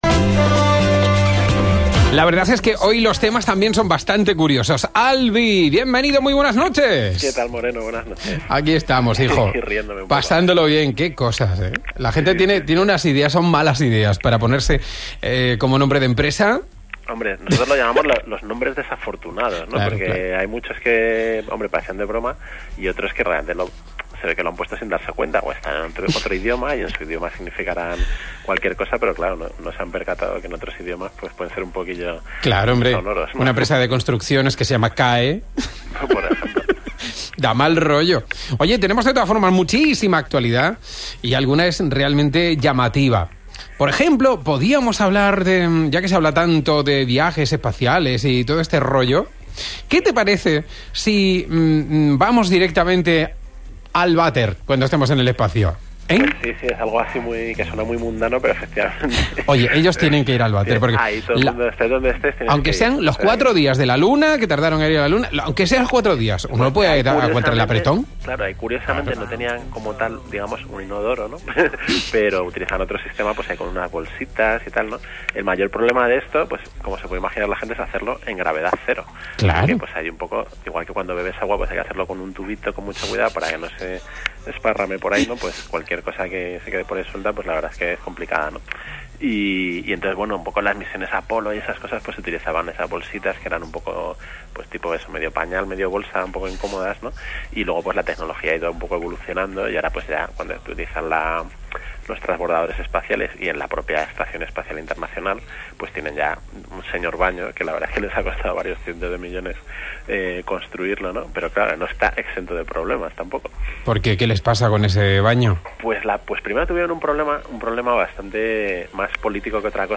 El audio con mi intervención está en el player de abajo [requiere Flash] o se puede descargar de